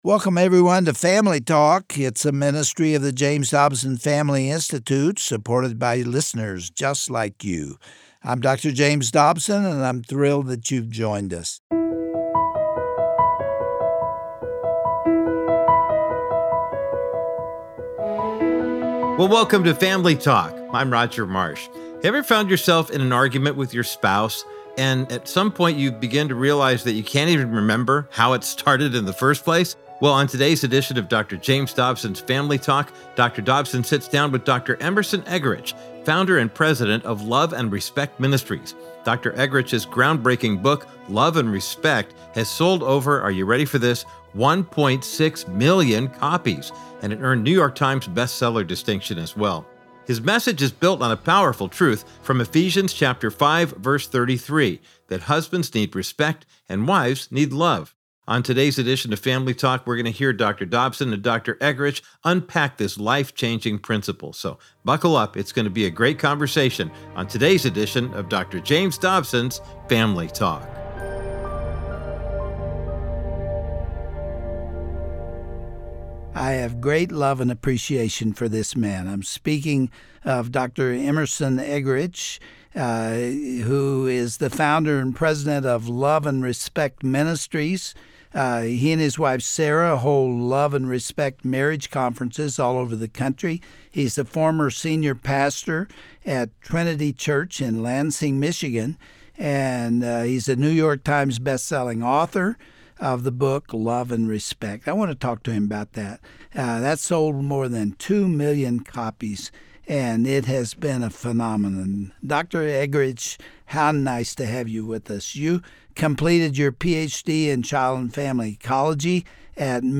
On today’s edition of Family Talk, Dr. James Dobson welcomes best-selling author Dr. Emerson Eggerichs to discuss his book, Love & Respect. He shares powerful biblical insights from Ephesians 5:33 about how husbands need respect and wives need love.